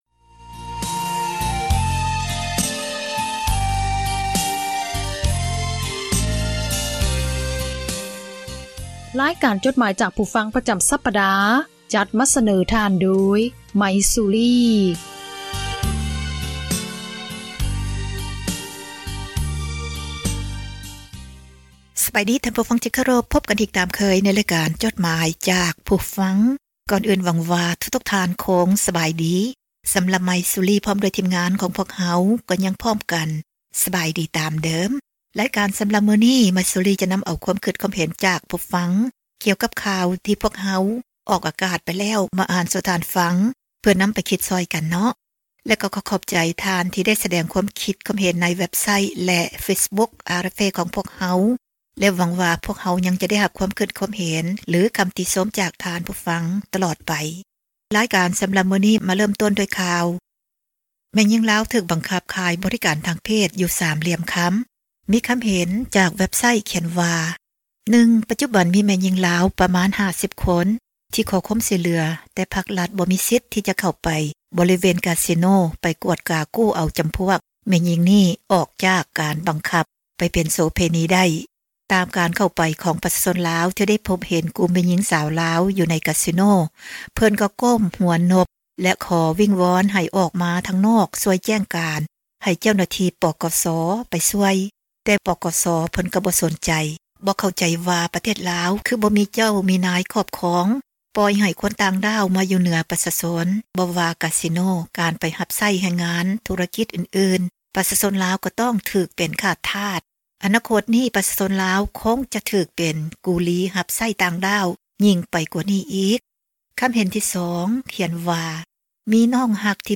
ຕິດຕາມມາດ້ວຍ ເພງ